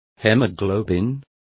Complete with pronunciation of the translation of hemoglobin.